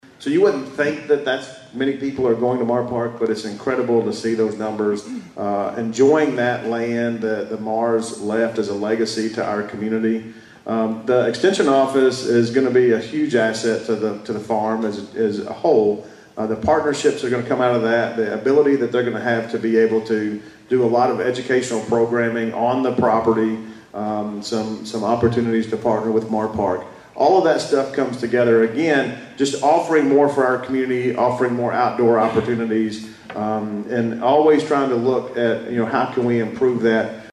During last week’s State of the Cities and County Address, Madisonville Mayor Kevin Cotton and Hopkins County Judge-Executive Jack Whitfield shared updates on local projects, including the addition of the new extension office at Mahr Park Arboretum.